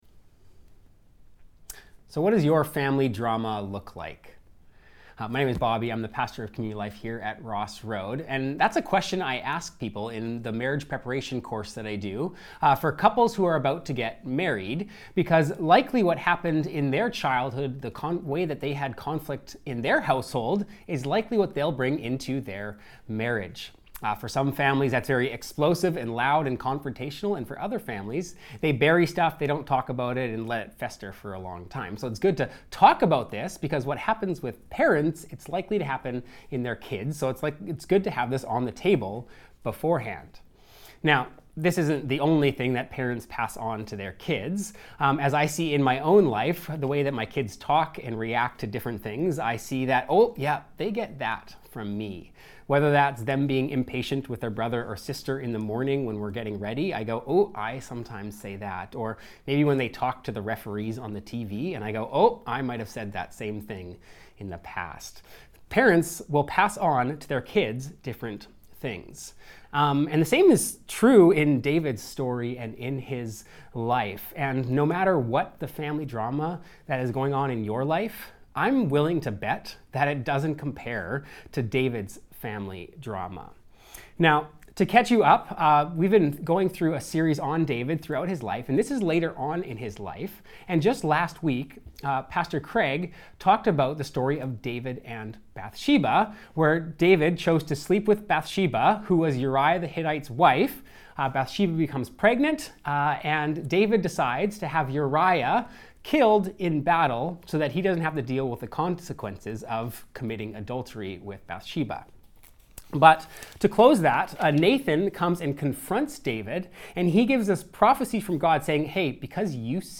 Current Sermon Back to the Wilderness How do you respond when you face a difficult season? David is betrayed by his son and closest counsellor and forced back into the wilderness.